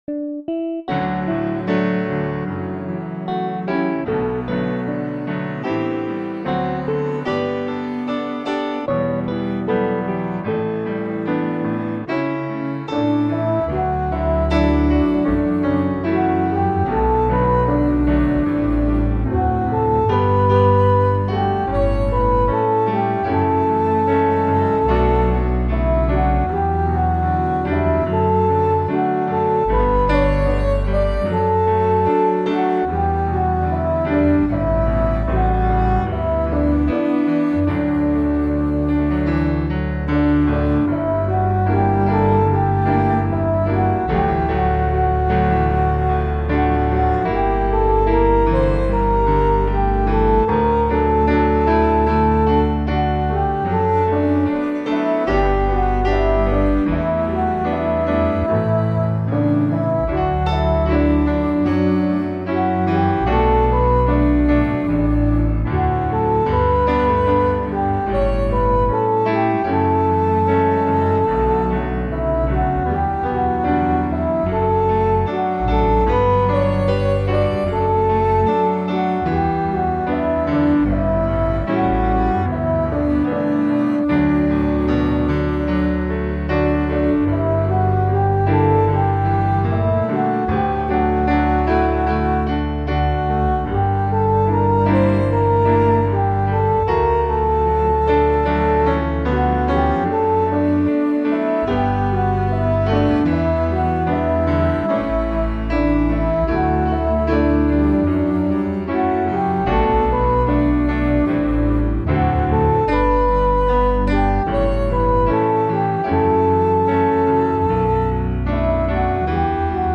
Here’s my backing to help with learning the piece: